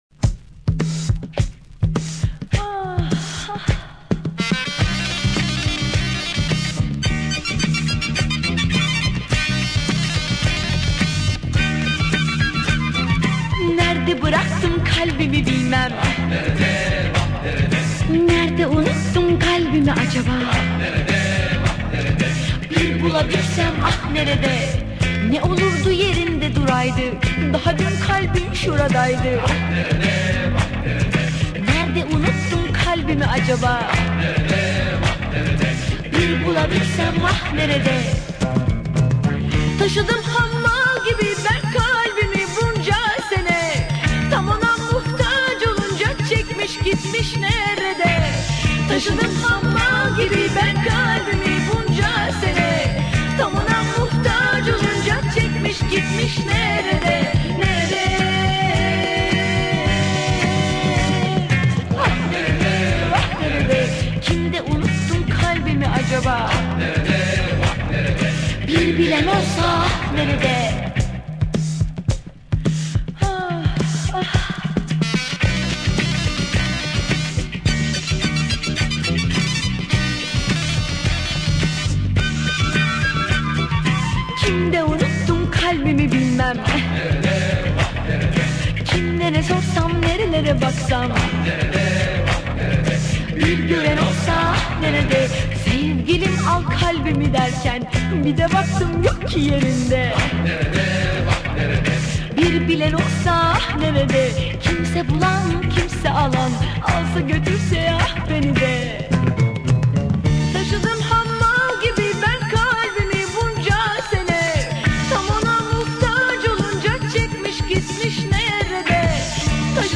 Turkish Pop, Pop